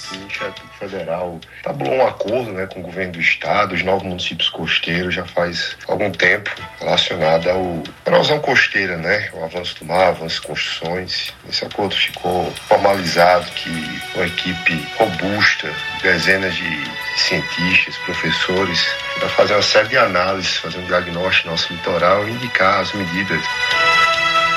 “O Ministério Público Federal estabeleceu um acordo com o Governo do Estado e os nove municípios costeiros já faz algum tempo relacionado à erosão costeira, né? O avanço do mar, avanço das construções… Esse acordo ficou formalizado que com a equipe robusta, dezenas de cientistas, professores, pra fazer uma série de análises e fazer um diagnóstico do nosso litoral e indicar as medidas”, afirmou durante entrevista ao programa Arapuan Verdade, da Rádio Arapuan FM.